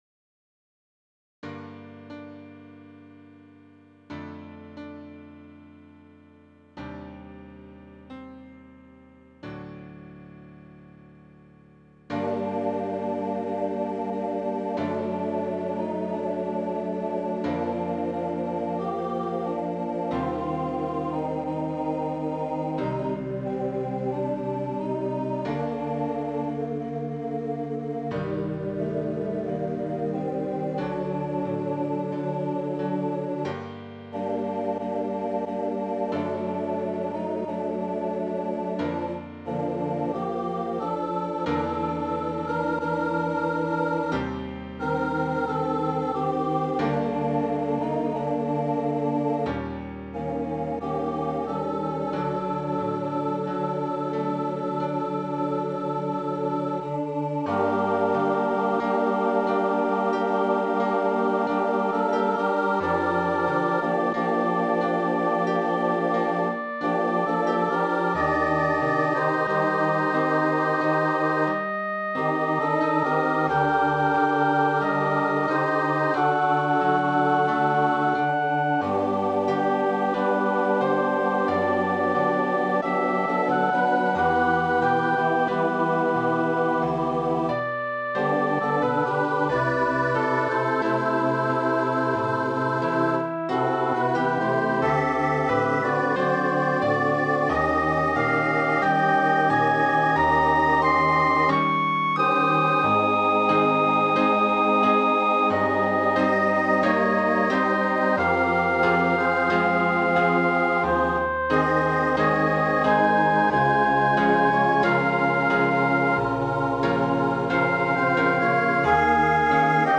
Gethsemane, SATB with flute
A tender song of love for the Savior's atonement in Gethsemane combined with acommitment to serve Him.
Voicing/Instrumentation: SATB